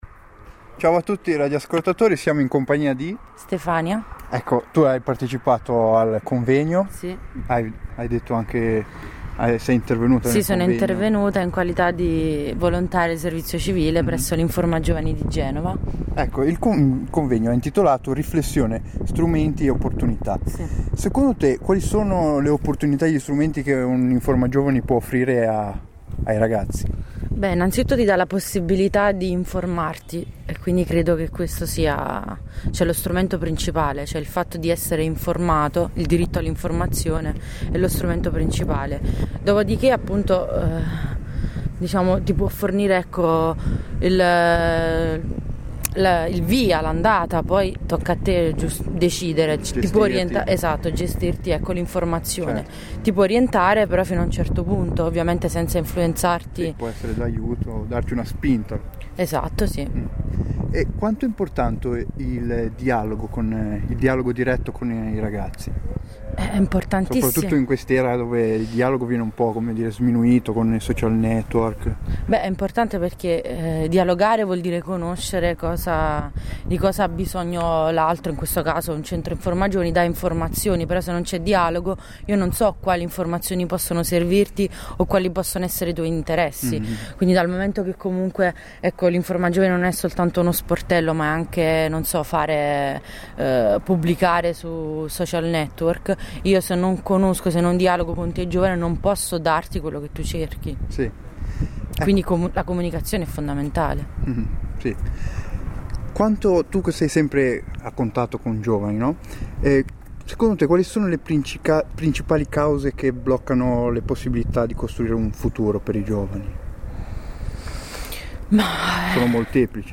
Intervista a Passante
Intervista di un passante durante il convegno degli Infogiovani del Tigullio